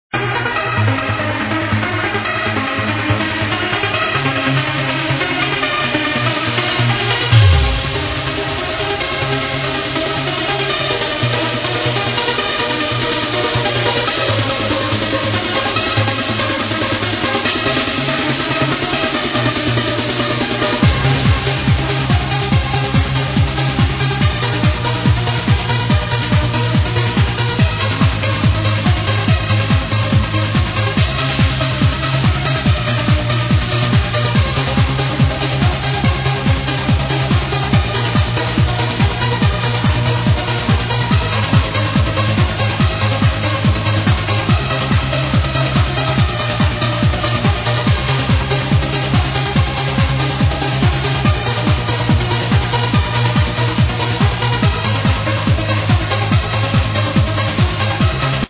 BEST UPLIFTING TRANCE TRACK OF ALL TIME... imho